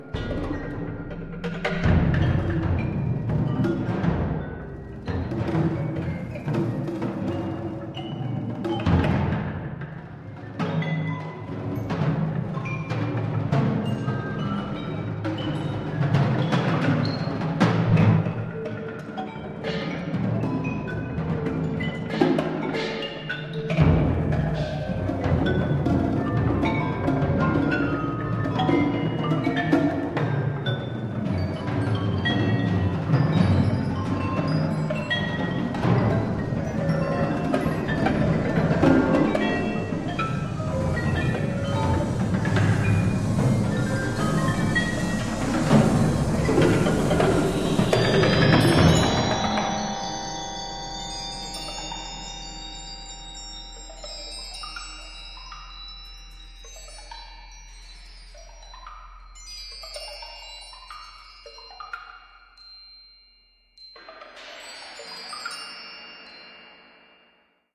for percussion sextet
and electronic
surround sounds